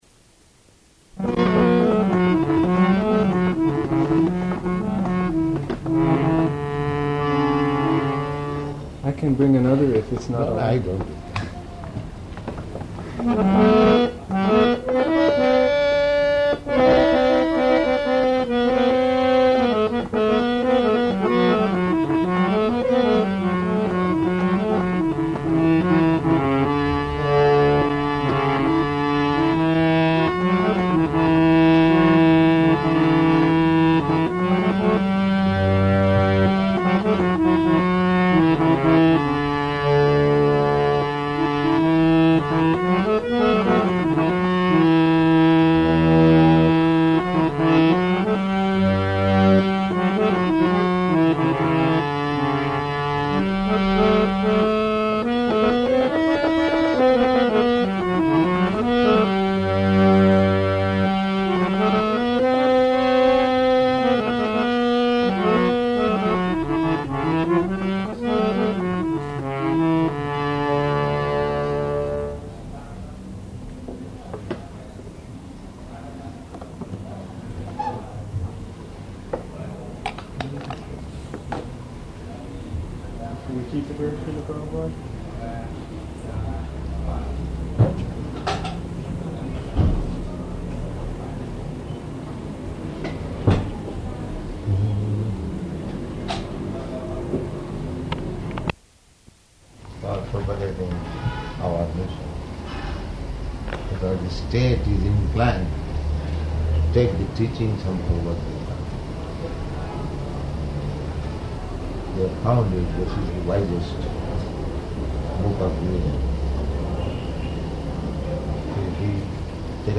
Room Conversation
Location: Jakarta
[Prabhupāda plays harmonium; no singing]